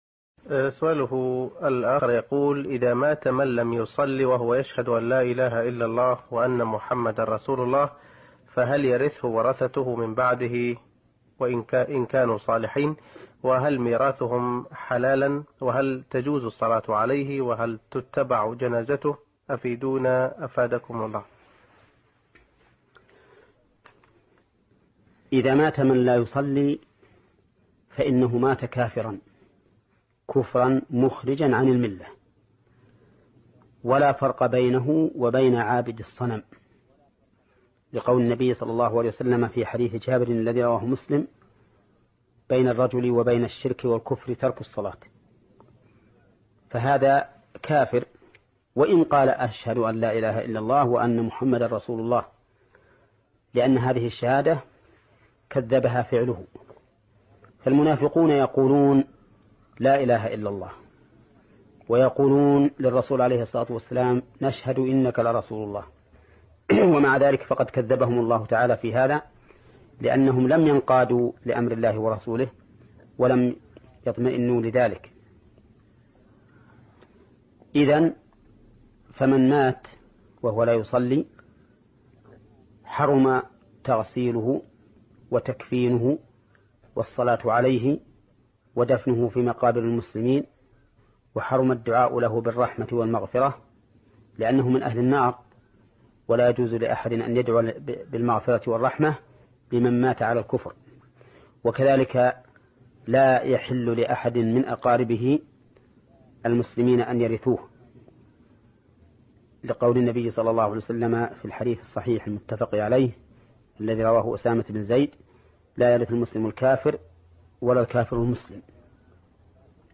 فتوى